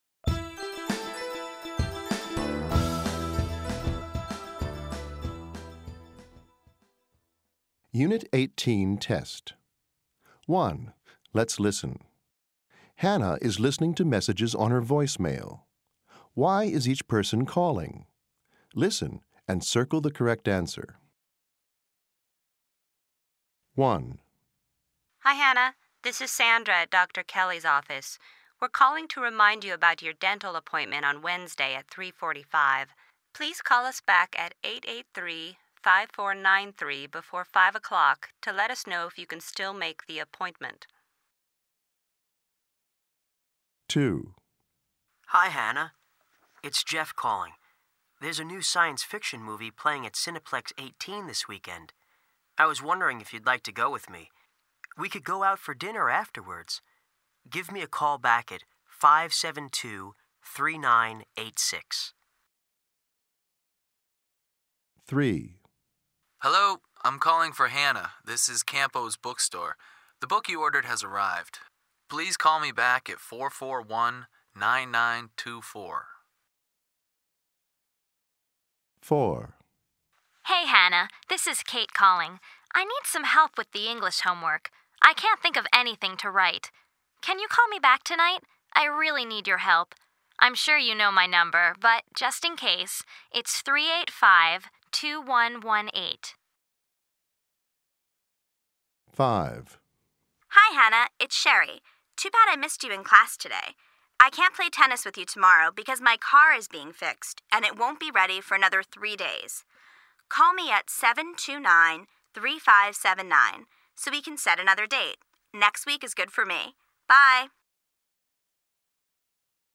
Hanna is listening to messages on her voicemail.